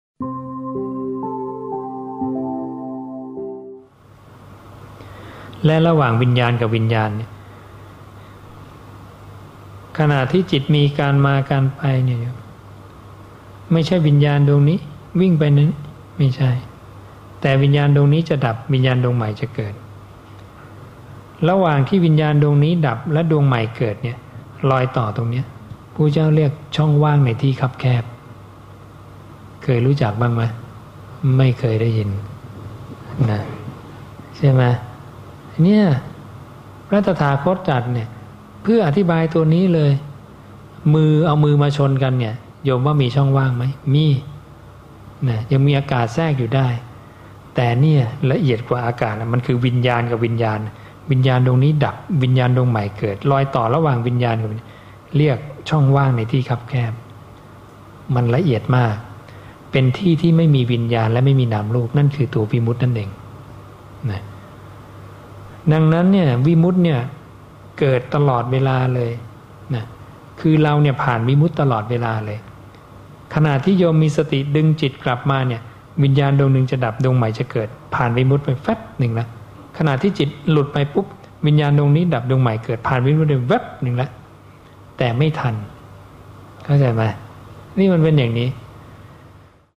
บางส่วนจากการแสดงธรรม ณ ยุวพุทธธิกสมาคม